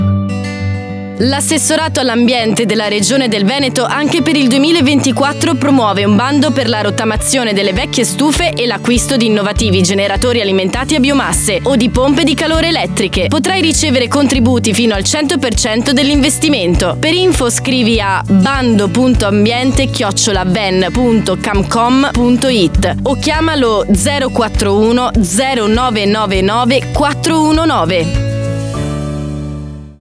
lo spot alla radio